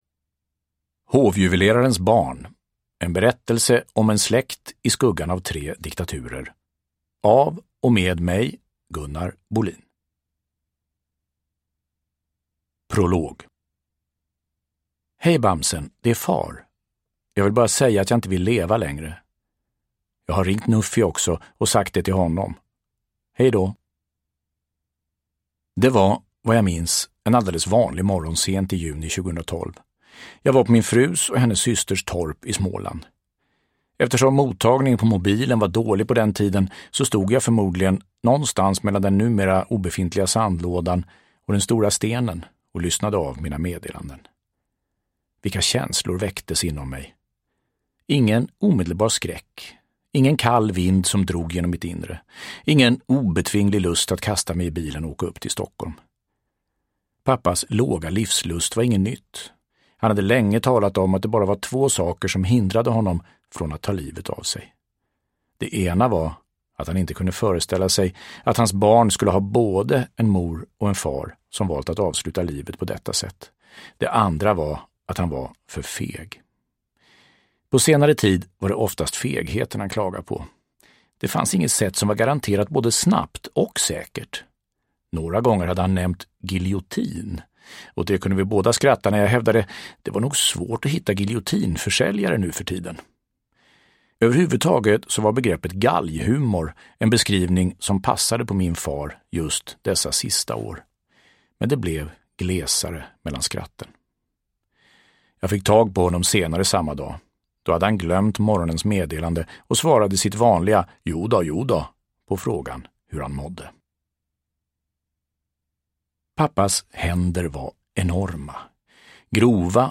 Uppläsare: Gunnar Bolin
Ljudbok